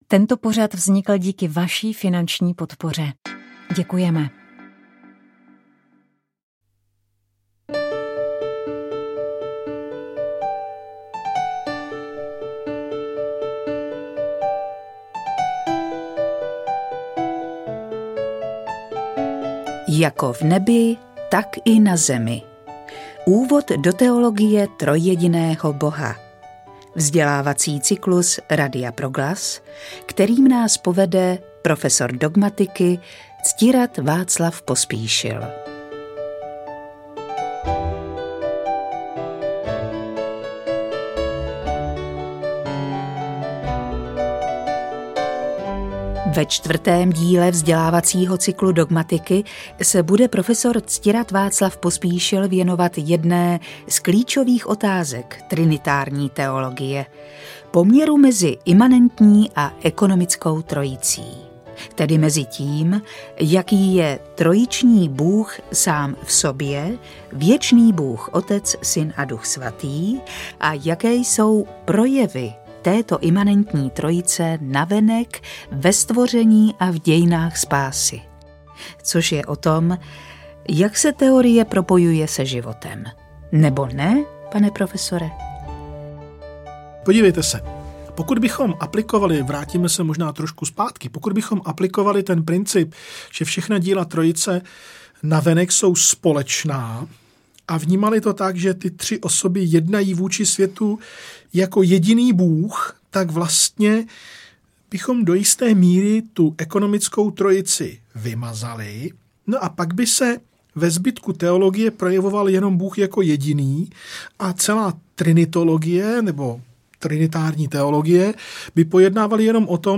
Čtení na pokračování: Muži v ofsajdu